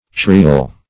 Search Result for " cerrial" : The Collaborative International Dictionary of English v.0.48: Cerrial \Cer"ri*al\, a. [L. cerreus, fr. cerrus a kind of oak.]